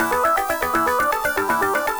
Index of /musicradar/8-bit-bonanza-samples/FM Arp Loops
CS_FMArp A_120-E.wav